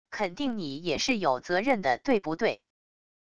肯定你也是有责任的对不对wav音频生成系统WAV Audio Player